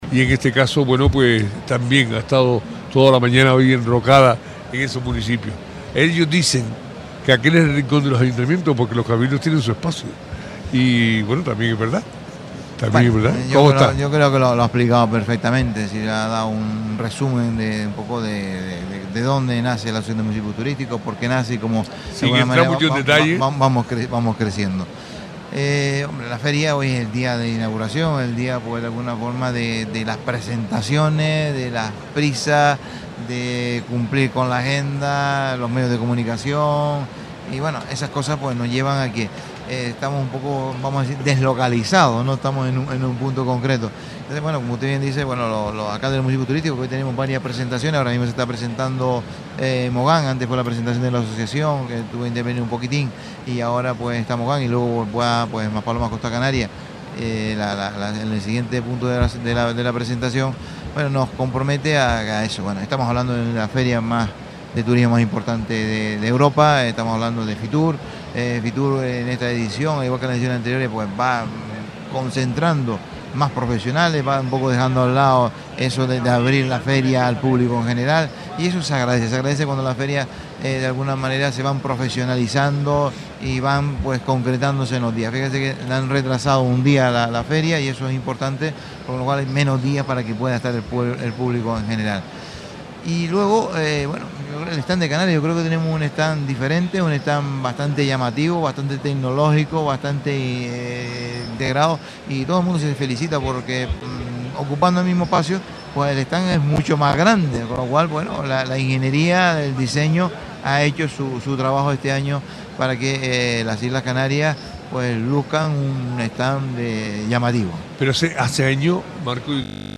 Radio Sintonía se desplaza un año más hasta Fitur para conocer el presente y futuro de la actividad turística en nuestra tierra en una feria que, según la visión de Marco Aurelio Pérez, vocal de la Asociación de Municipios Turísticos de Canarias, evoluciona a la profesionalización.